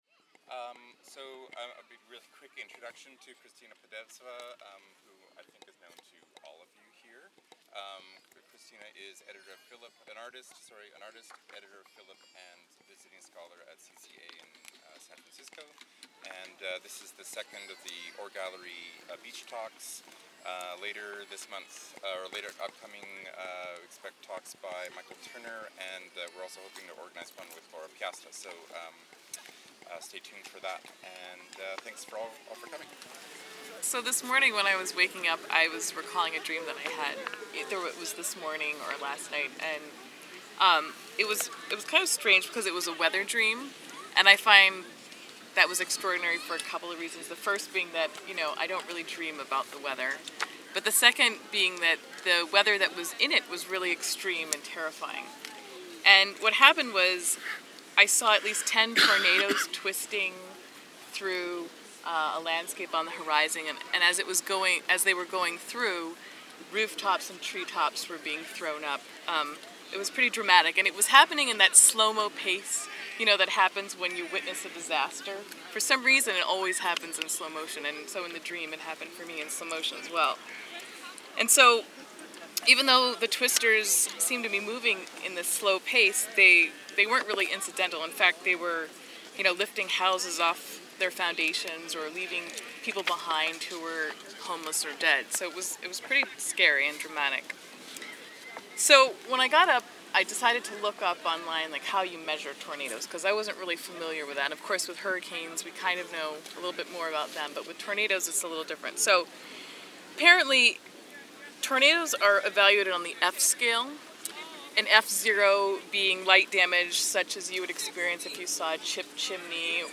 Part recitation, part listening party, Going Under takes inspiration from a reading of Herman Melville’s 1888 John Marr alongside a presentation of sea shanties and African American work songs to ...